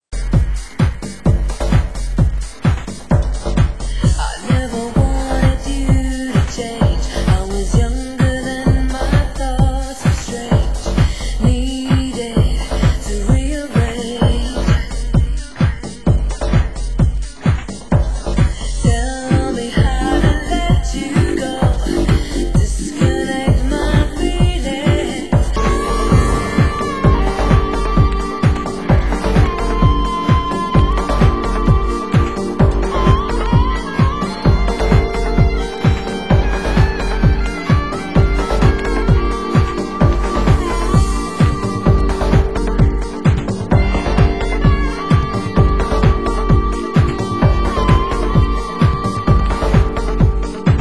Genre: UK House
vocal club mix
harmonica dub mix